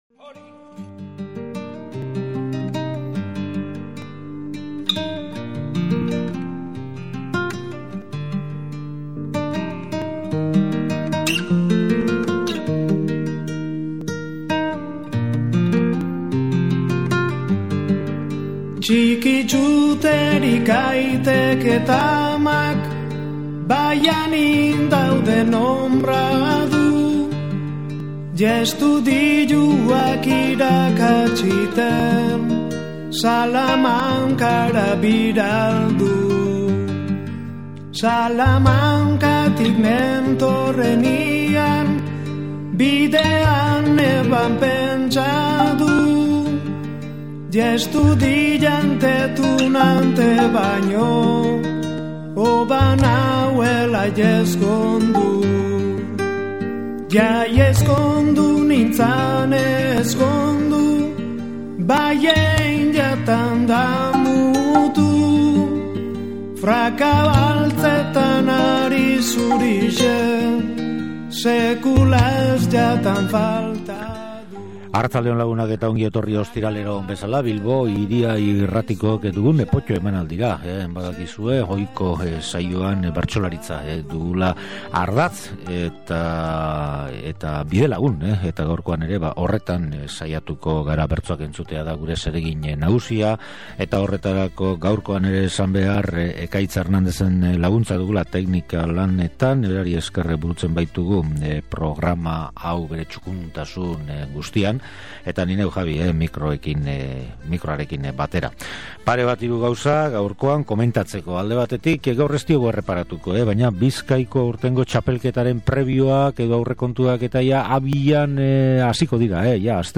Efemerideen eguna izan da gaurkoa Potto irratsaioan.
Horretarako, lehen kasuan Bilintxen Kontxesirentzat bertsoak izeneko lan ahaztezina jarri du entzungai eta, bigarren efemerideari dagokionez, Basarrik 1938an Gernikako bonbardaketaren ondorioez egindako bertsoak entzun ahal izan ditugu. Horrezaz gainera, Bilboko Kafe Antzokian duela hiru urte egin zen bertso afarian Maialen Lujanbiok eta Sebastian Lizasok saio librean botatako bertsoak gogoratu ditugu. Azkenik, bertsozaleek hurrengo egunetan dituzten hitzorduak aipatzen dira.